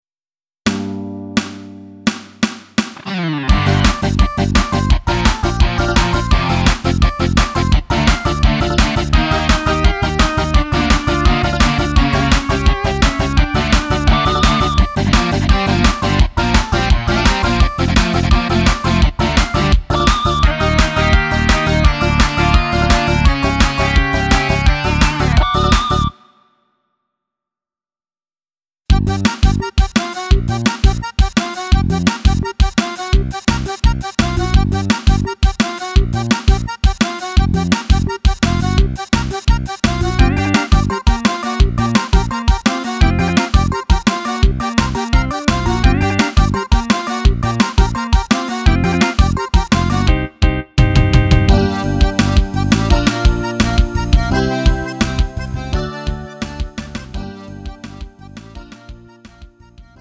음정 원키 3:49
장르 가요 구분